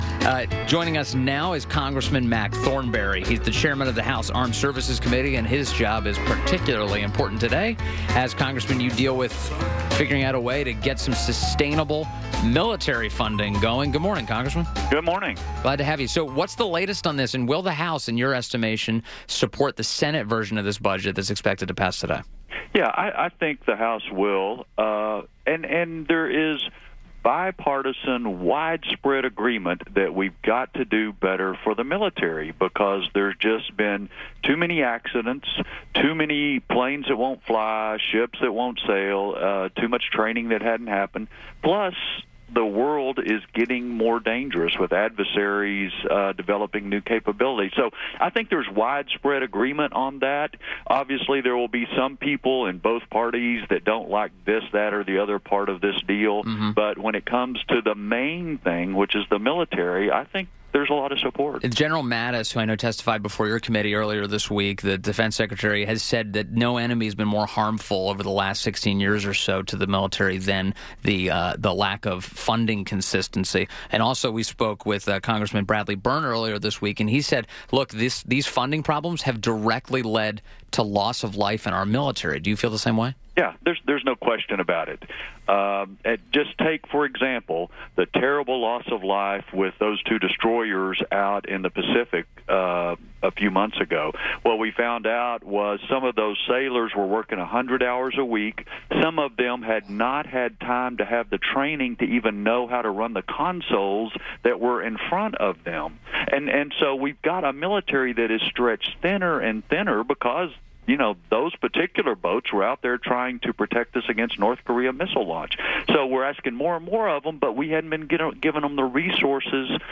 INTERVIEW - Congressman Mac Thornberry - (R-TX) - Chairman of the House Armed Services Committee – discussed President Trump’s parade idea and how it impacts military spending